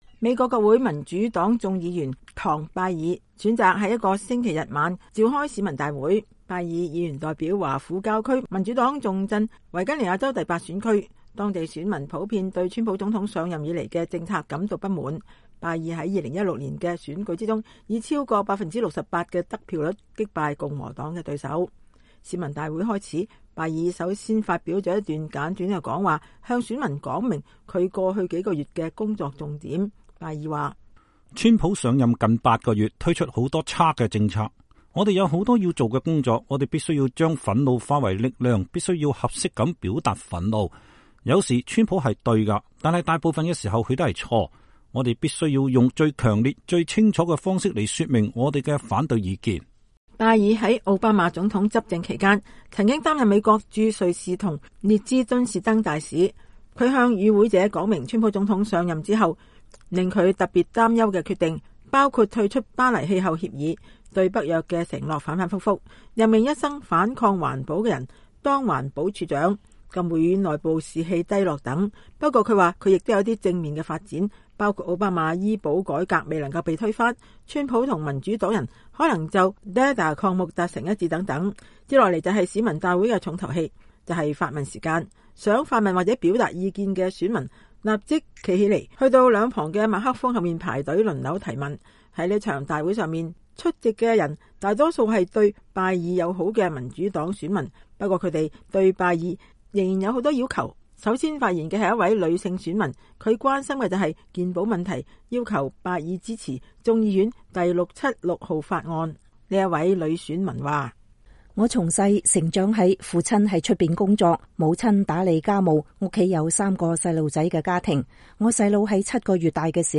維吉尼亞州市民大會 採訪拜爾議員